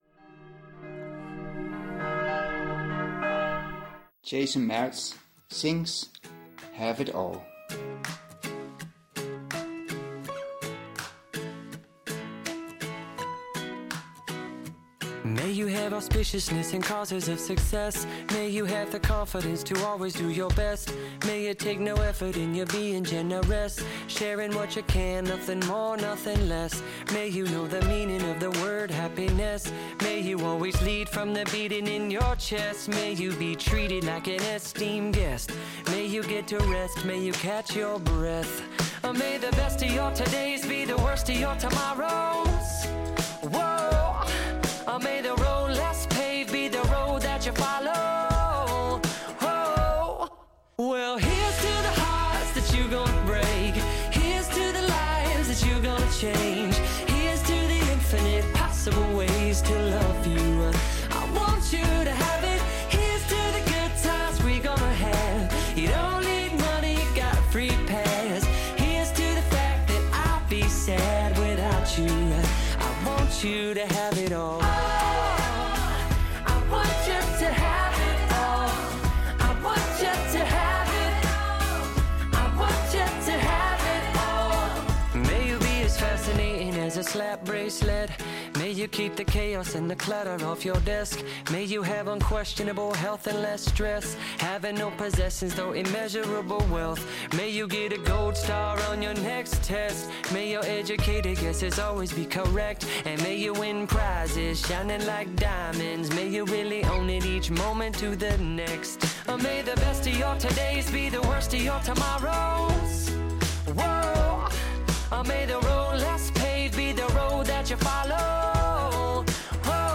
Meditationspodcast für die Woche von Sonntag, den 9. August
Die heutige Lesung ist aus dem Evangelium nach Matthäus, Kapitel 5, Vers 13 bis 16.